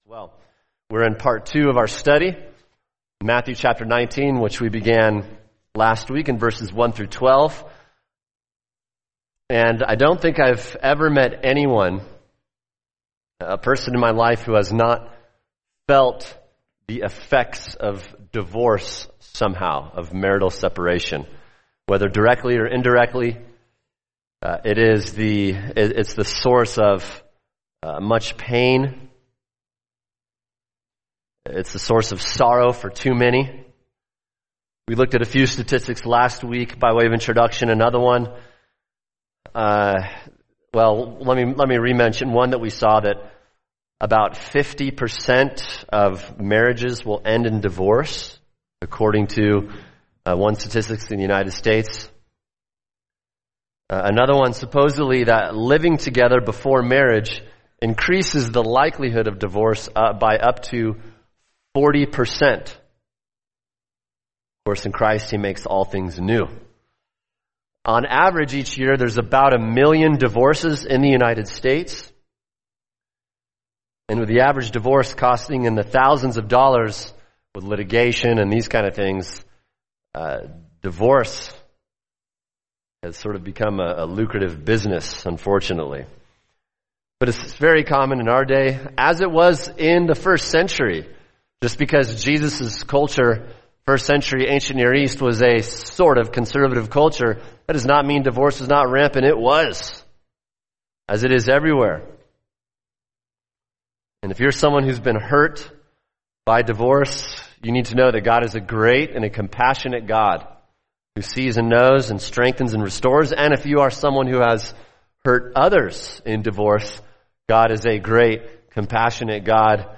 [sermon] Matthew 19:1-12 Marriage and Divorce – Part 2 | Cornerstone Church - Jackson Hole